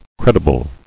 [cred·i·ble]